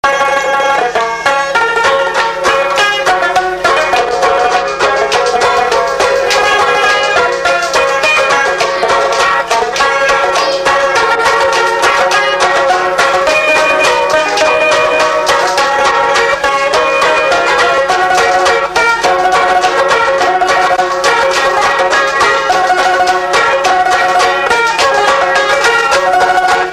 Instrumental
danse : fox-trot
Pièce musicale inédite